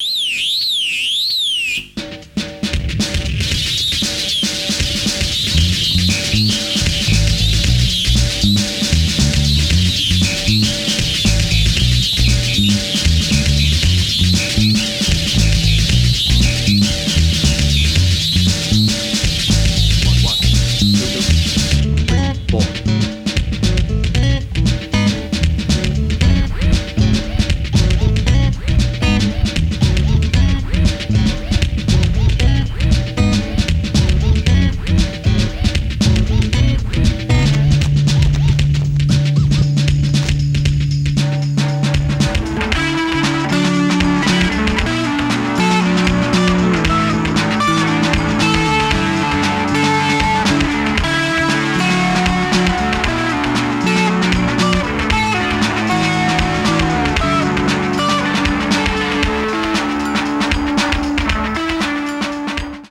(1:02) Some underground industrial beat